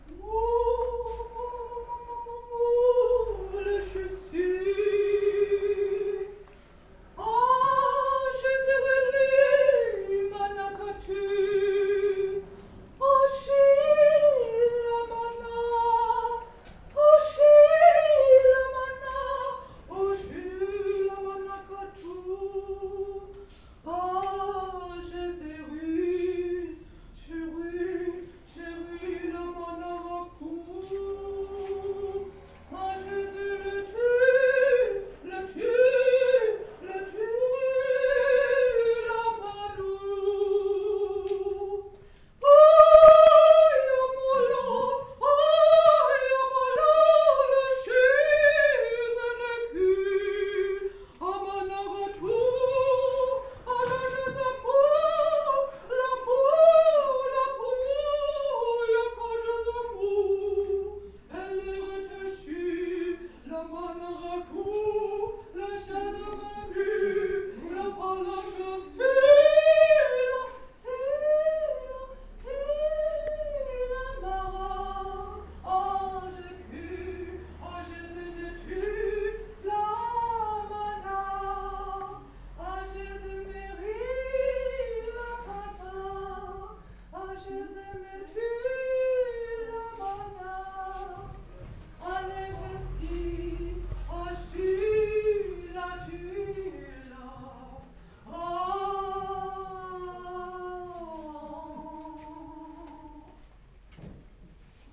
Die live and unplugged Aufnahme ist hier als Hörbeitrag bereitgestellt.